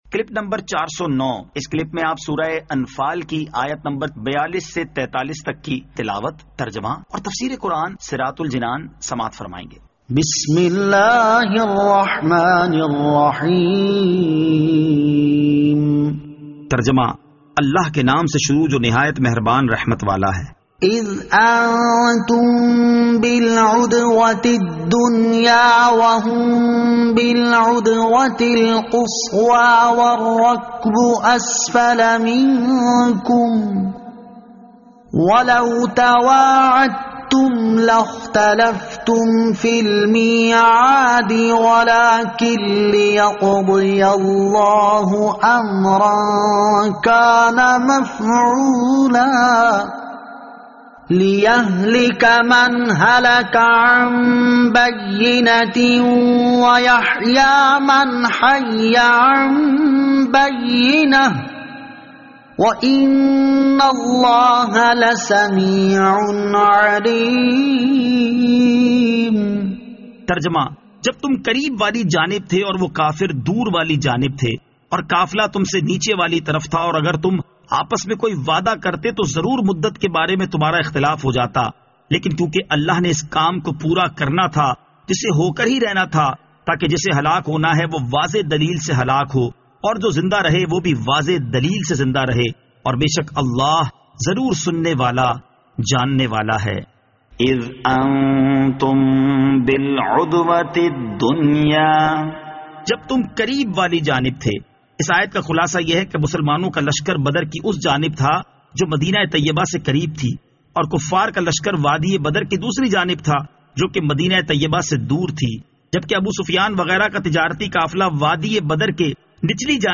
Surah Al-Anfal Ayat 42 To 43 Tilawat , Tarjama , Tafseer